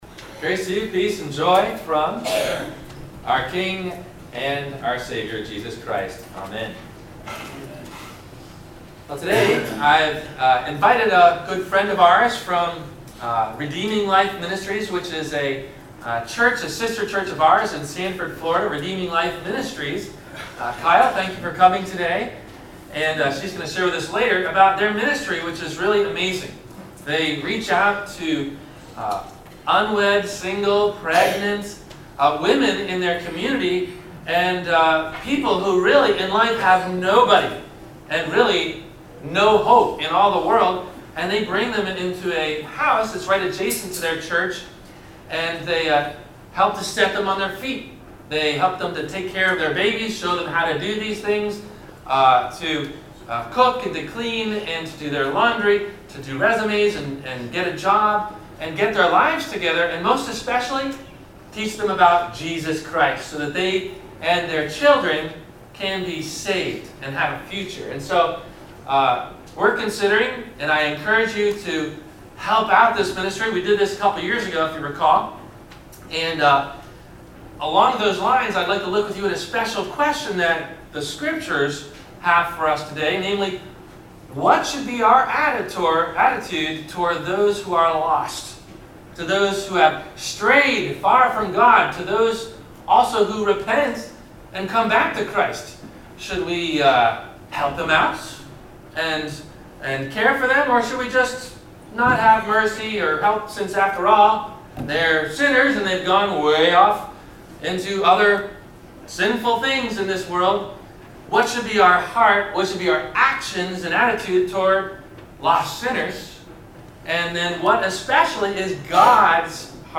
- Sermon - January 21 2018 - Christ Lutheran Cape Canaveral